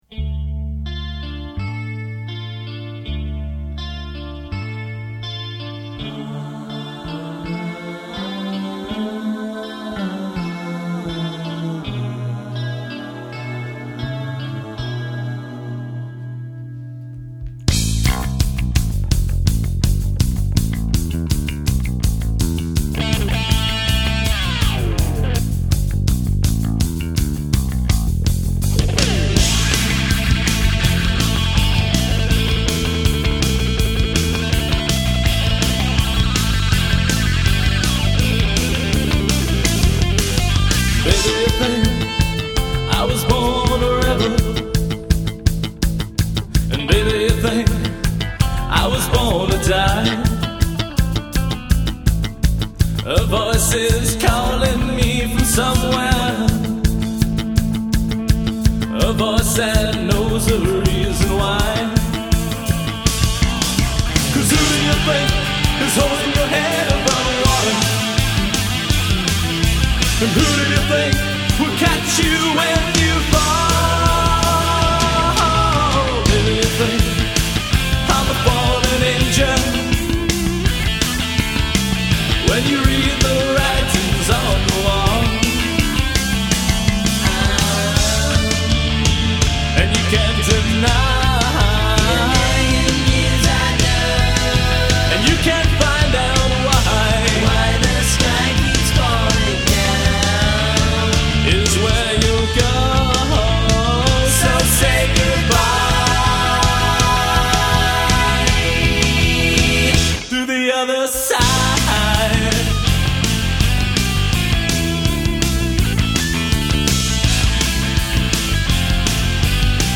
• (D) Sang Lead Vocals
• (F) Played Drums